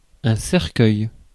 Ääntäminen
Synonyymit bière boîte caisse sapin mazout Ääntäminen France: IPA: /sɛʁ.kœj/ Haettu sana löytyi näillä lähdekielillä: ranska Käännös Ääninäyte Substantiivit 1. bier 2. casket 3. coffin US Suku: m .